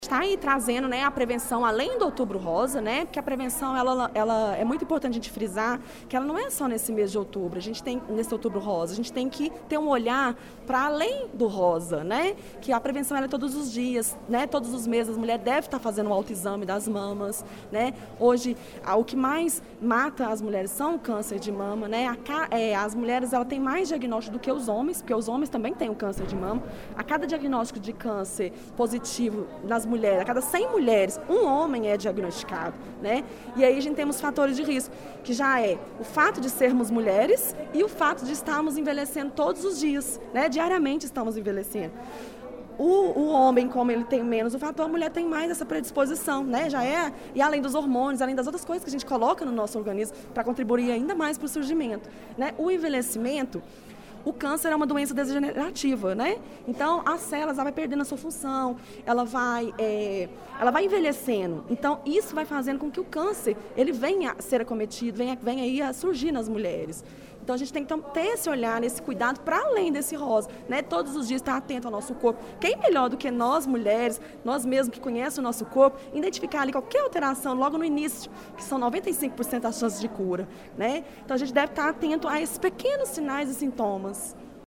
Às mulheres que aguardavam por atendimento, ela falou sobre a importância do auto exame e de procurar ajuda quando notarem algo diferente nas mamas: